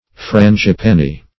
Frangipani \Fran`gi*pan"i\, Frangipanni \Fran`gi*pan"ni\, n.